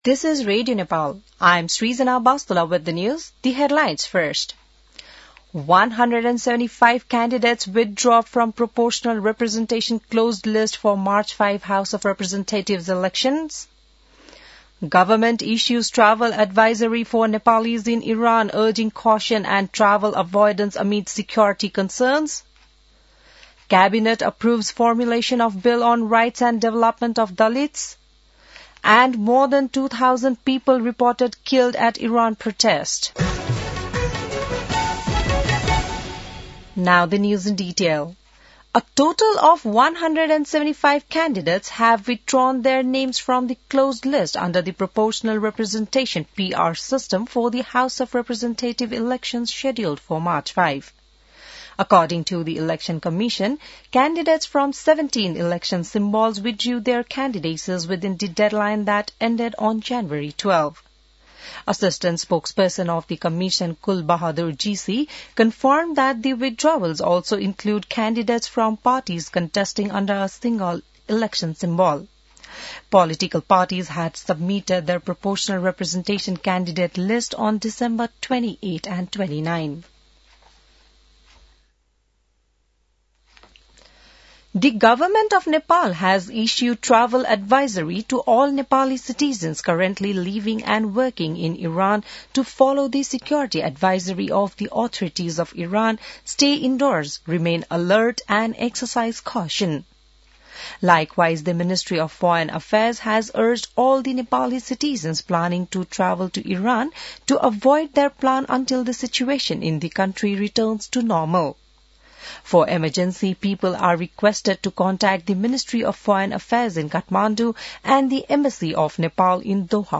बिहान ८ बजेको अङ्ग्रेजी समाचार : ३० पुष , २०८२